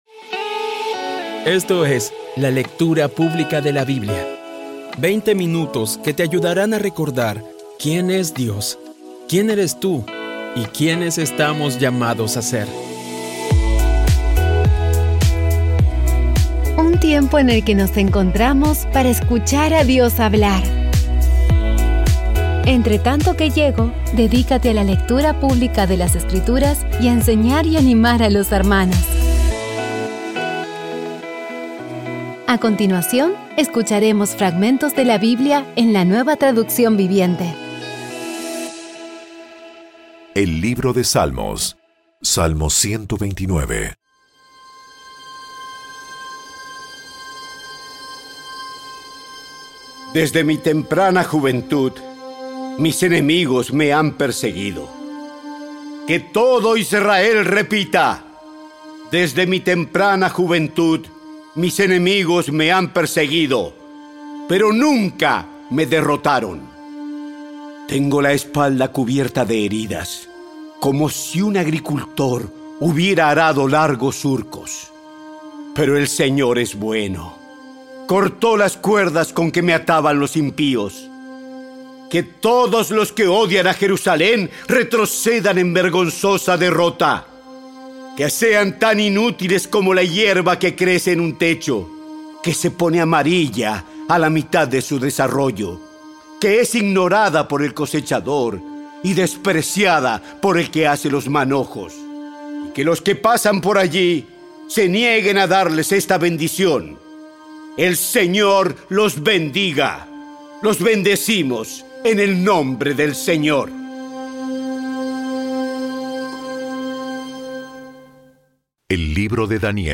Audio Biblia Dramatizada Episodio 327
Poco a poco y con las maravillosas voces actuadas de los protagonistas vas degustando las palabras de esa guía que Dios nos dio.